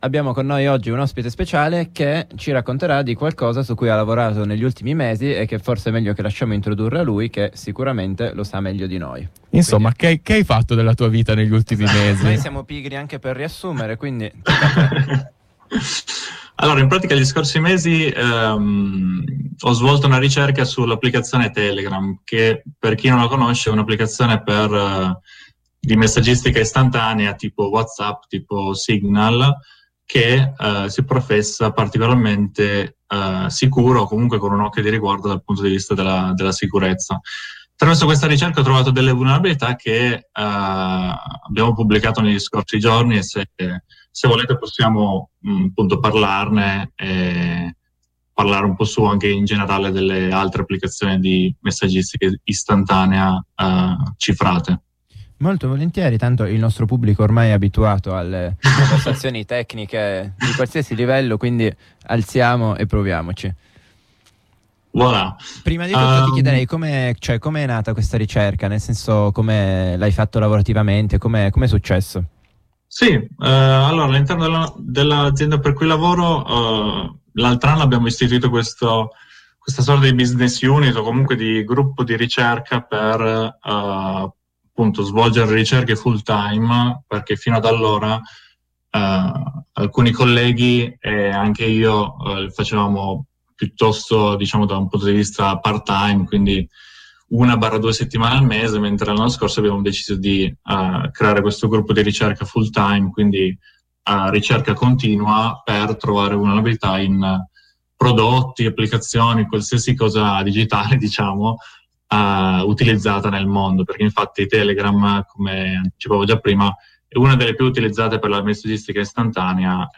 Puntata centrata sull'intervista a chi, con mesi di ricerche, è riuscito a trovare diverse vulnerabilità di sicurezza in Telegram.